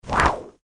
点赞音效礼物弹窗音效
ui.MP3